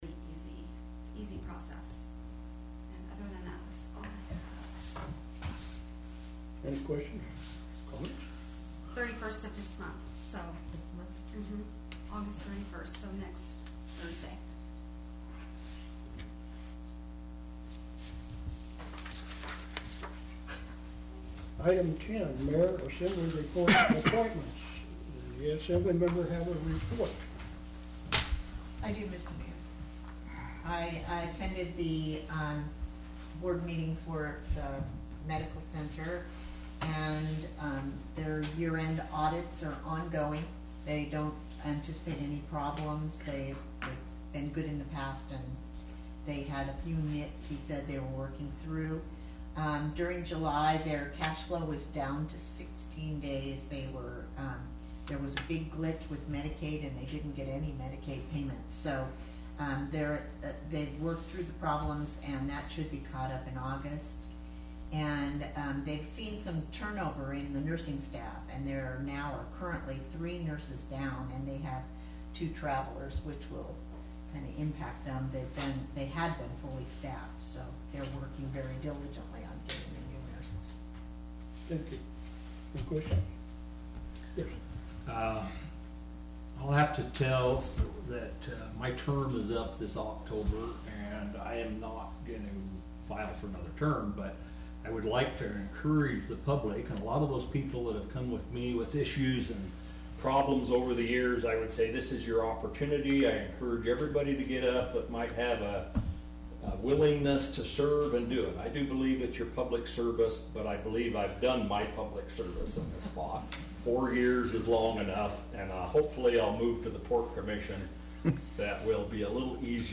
The Wrangell Borough Assembly held a regular meeting on Tuesday, August 22, 2017.
Tuesday, August 22, 2017 7:00 p.m. Location: Assembly Chambers, City Hall